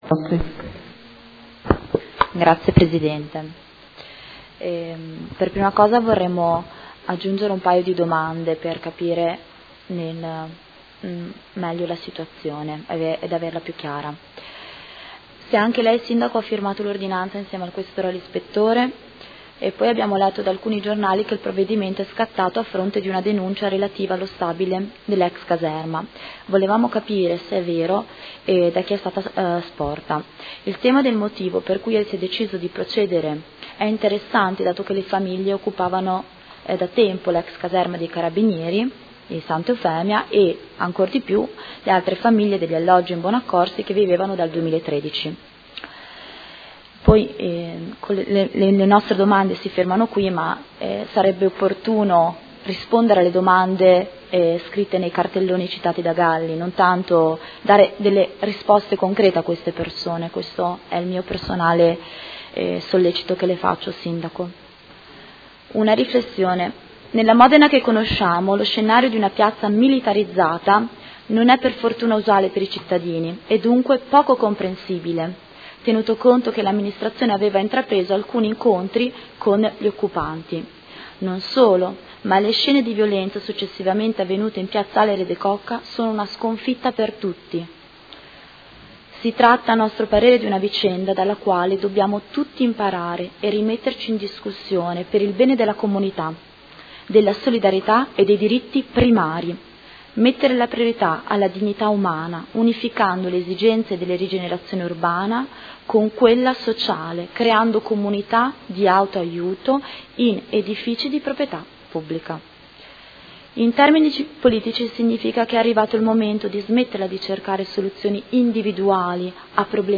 Seduta del 19/05/2016. Interrogazione del Gruppo Per Me Modena avente per oggetto: Sgomberi degli stabili occupati e questione abitativa a Modena.